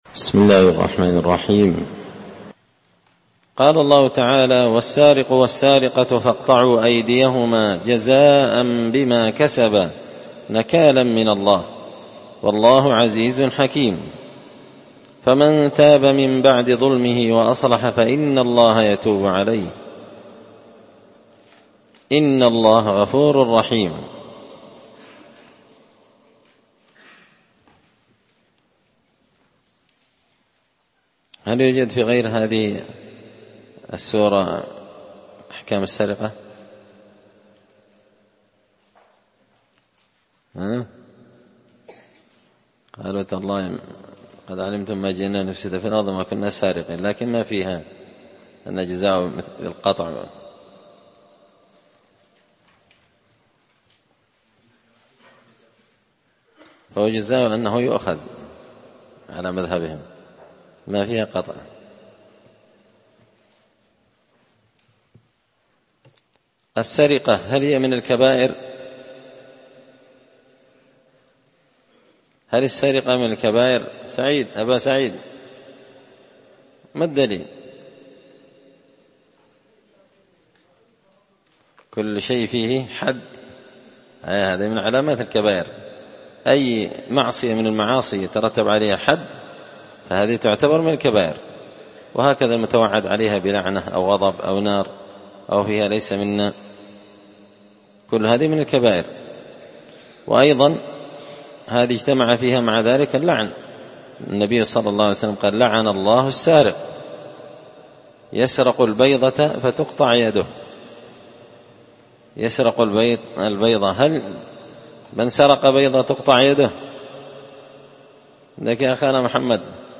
مسجد الفرقان